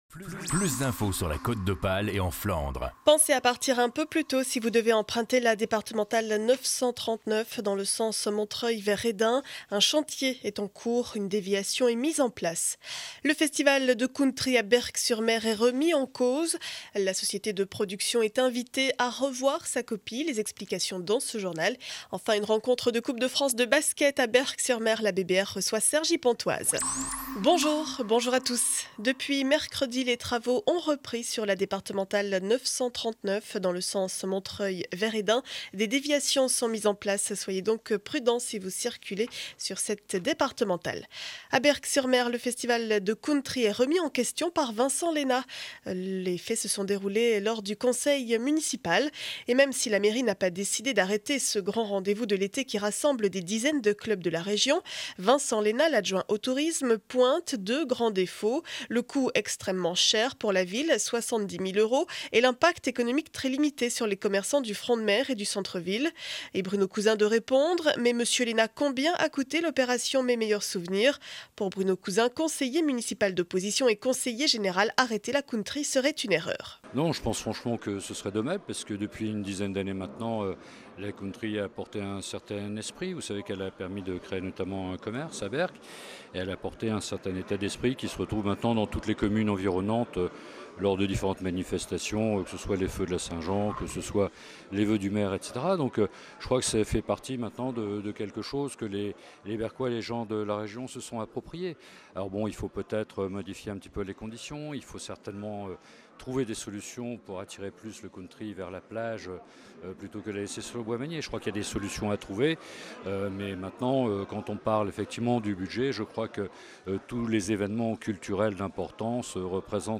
Journal du vendredi 02 mars 2012 7heures 30 édition du Montreuillois.